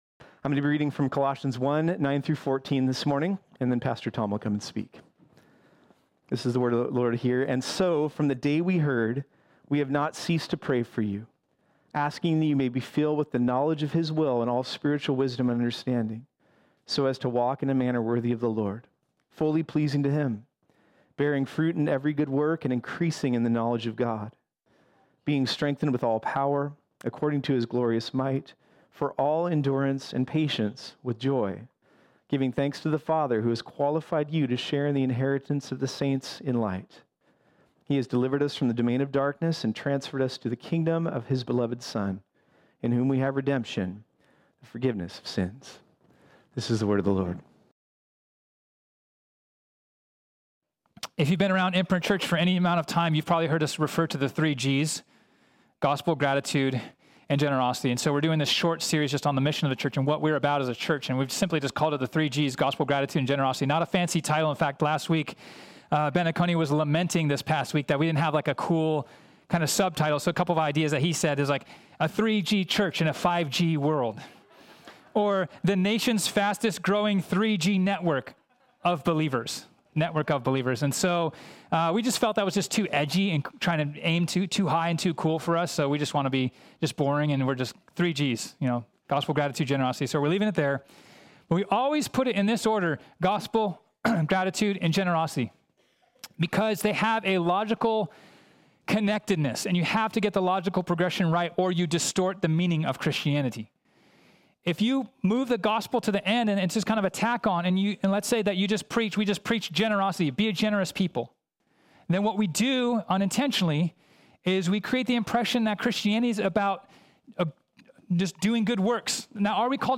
This sermon was originally preached on Sunday, January 10, 2021.